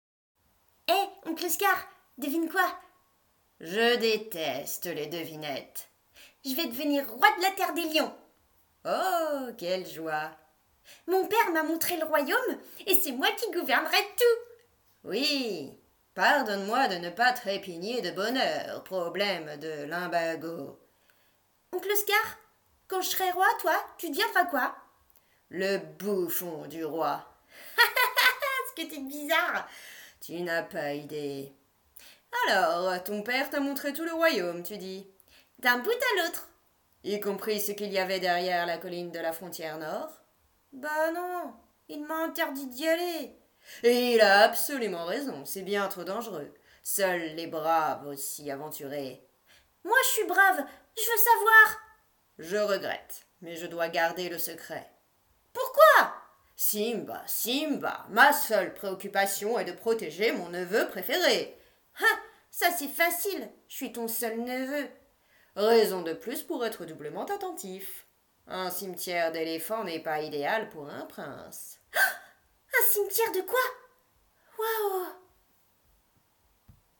Dessin animé - le Roi Lion
14 - 34 ans - Soprano